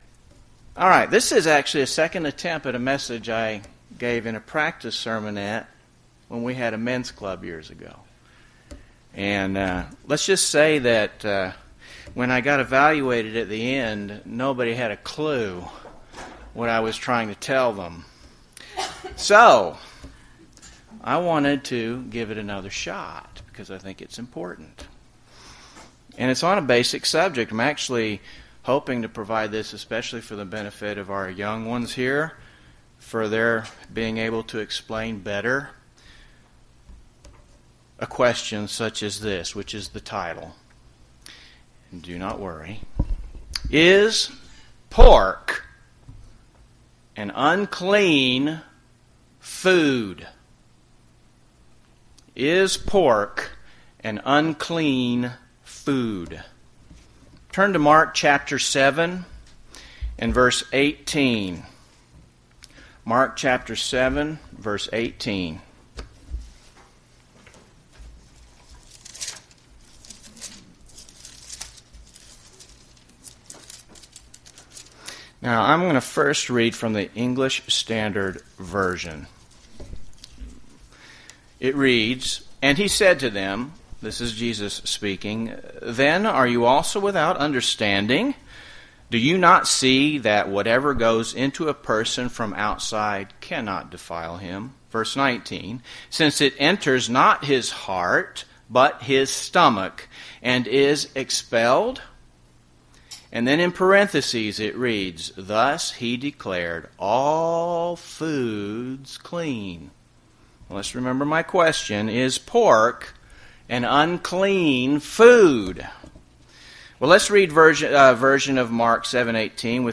UCG Sermon Leviticus 11 Deuteronomy 14 unclean meats dietary food laws Notes PRESENTER'S NOTES Mark 7: 18 And he said to them, Then are you also without understanding?